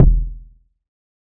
Start It Up Kick.wav